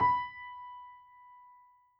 piano_071.wav